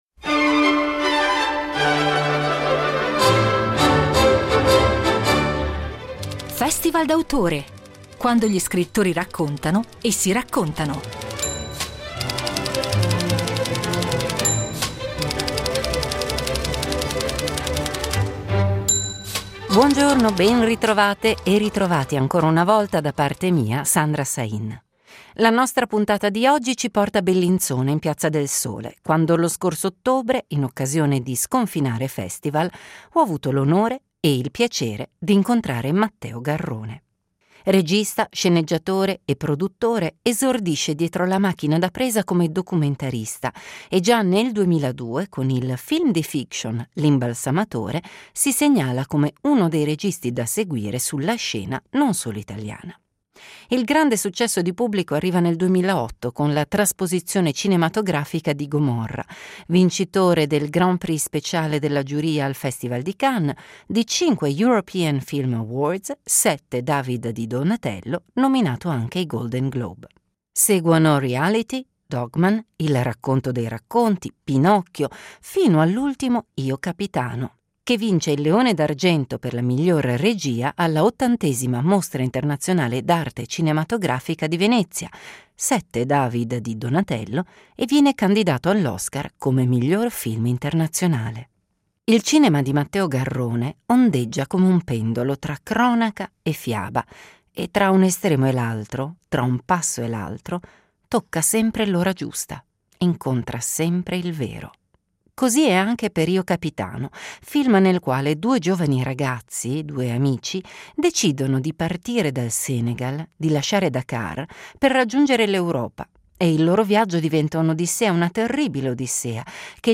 Incontro a Sconfinare Festival